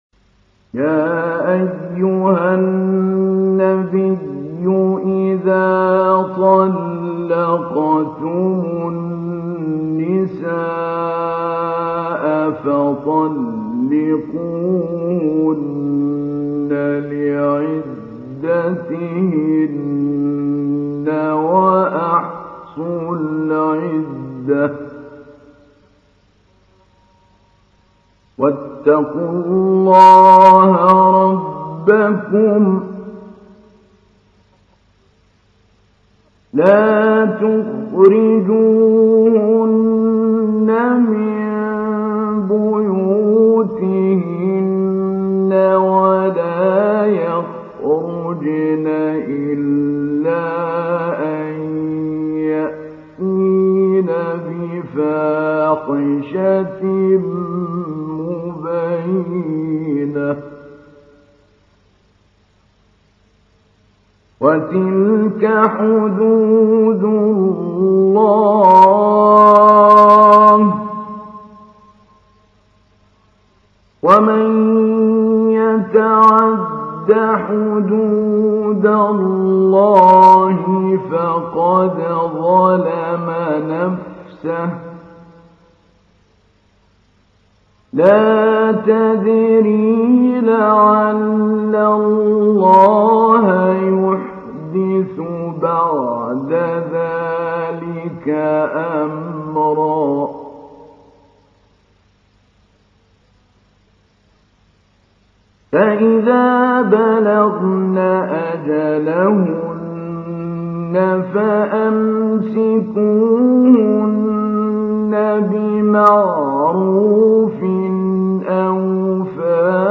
تحميل : 65. سورة الطلاق / القارئ محمود علي البنا / القرآن الكريم / موقع يا حسين